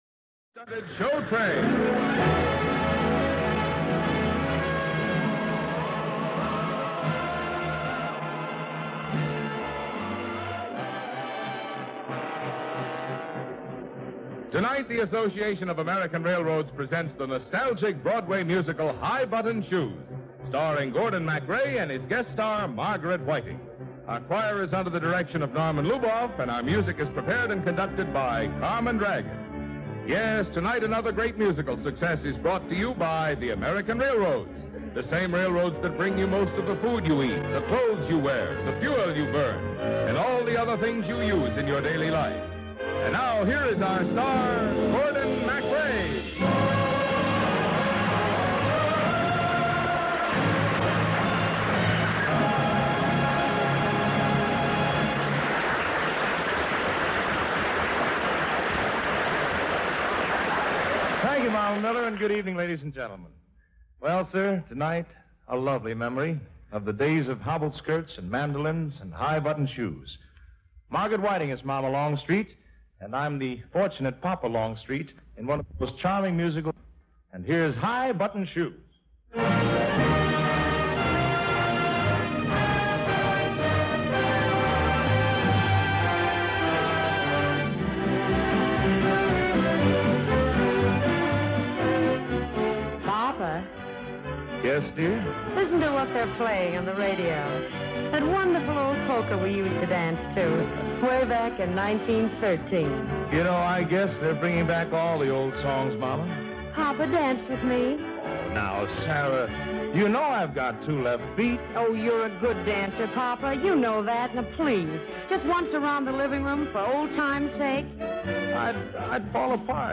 musical dramas and comedies